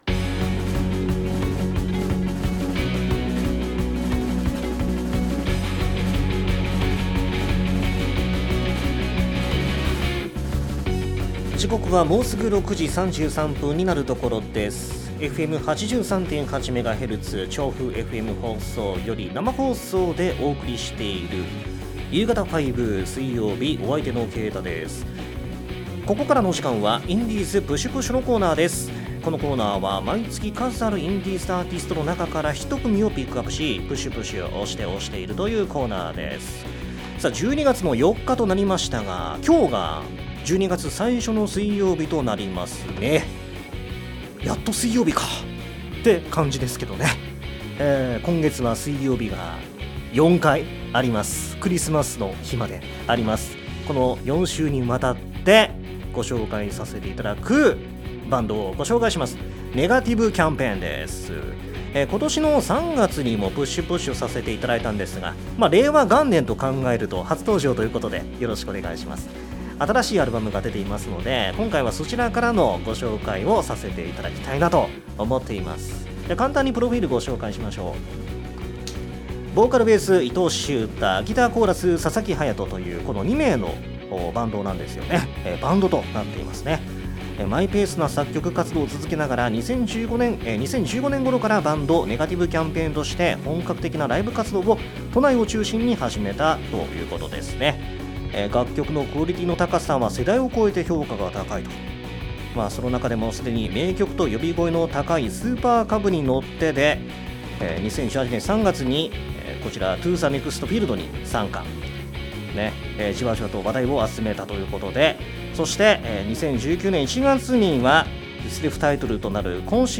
※楽曲カットしています
<同録音源>